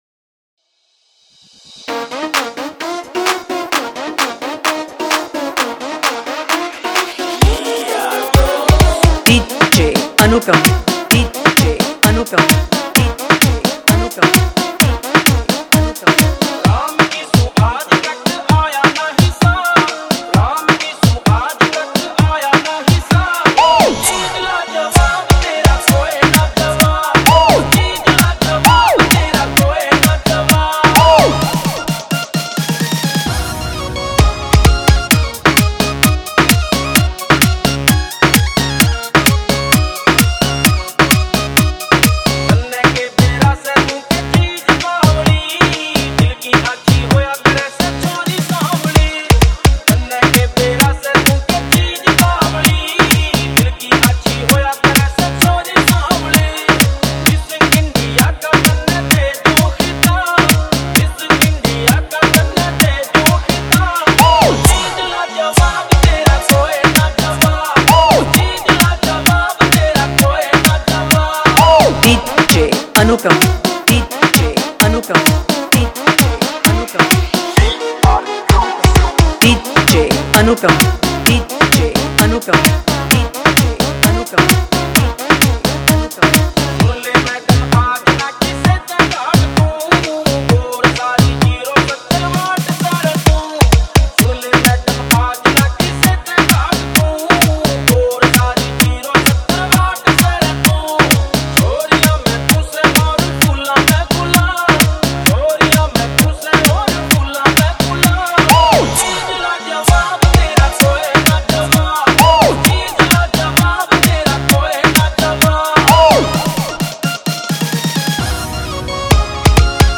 DJ Remix Songs